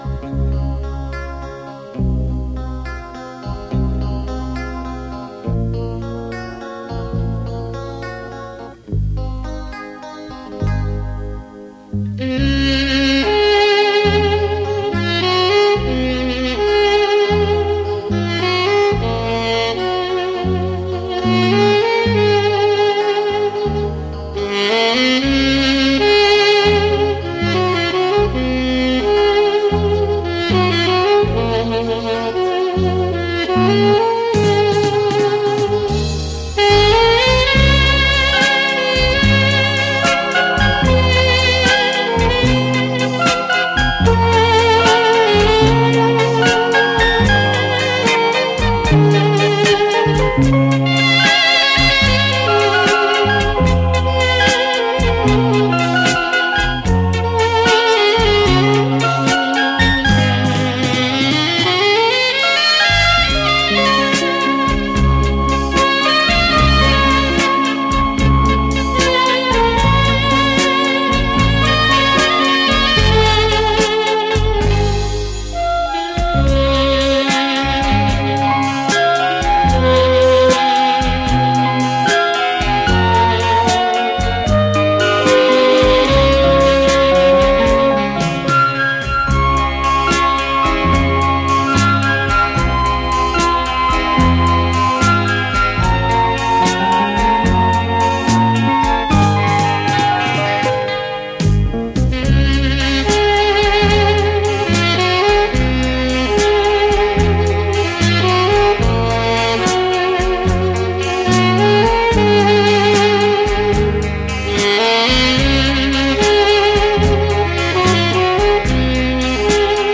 这是一张非常流行的现代轻音乐唱片
选曲精辟短小，美妙传情，抒发了人们自信、快乐的情绪。
磁带数字化